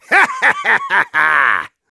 Voice file from Team Fortress 2 German version.
Spy_laughevil01_de.wav